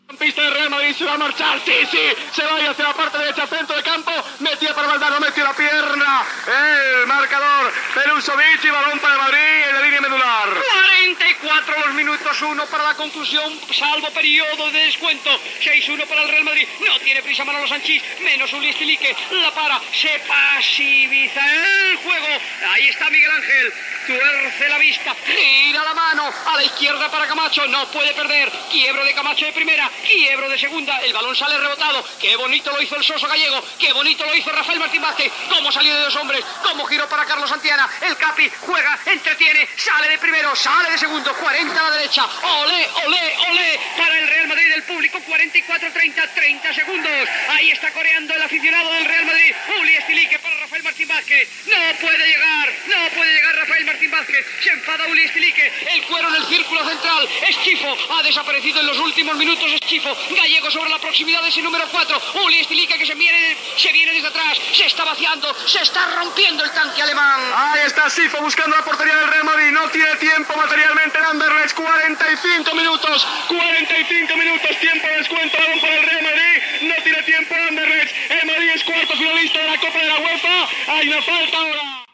Narració del partit de tornada de la Copa de la UEFA de futbol masculí entre el Real Madrid i l'Anderlecht. Minut, resultat i narració de les jugades del final del partit.